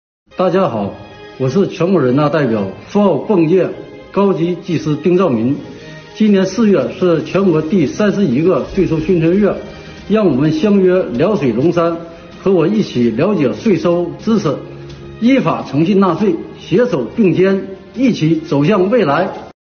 我们诚邀全国人大代表丁照民，为第31个全国税收宣传月积极发声、助力添彩。